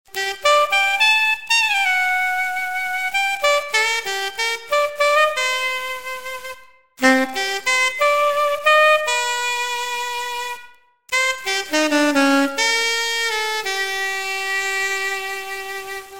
different mouthpiece/reed setup (see below)